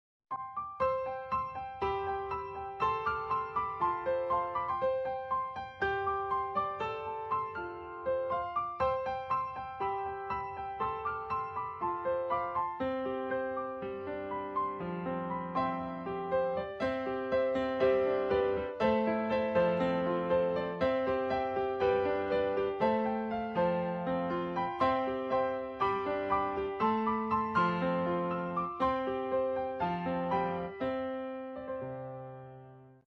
Watch them sprint outside, chase frisbees, and share a hilarious ‘conversation’ with each other.